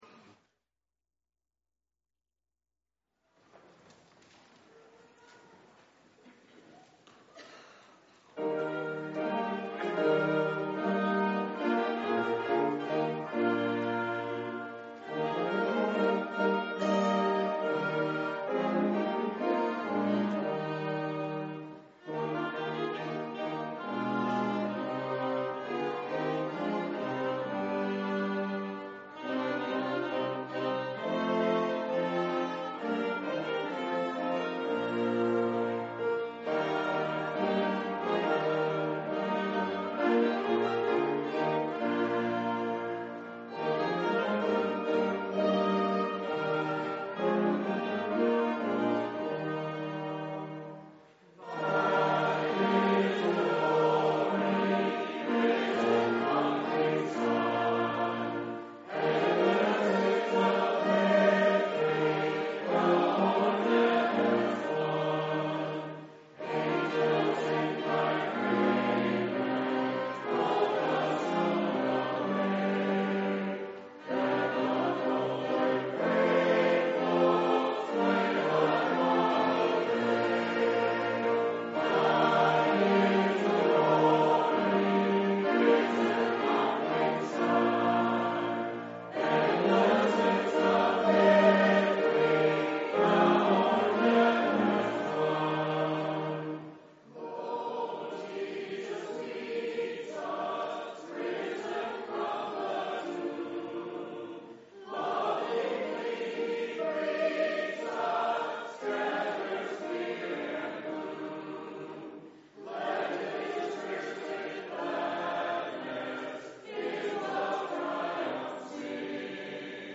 Lots of good music!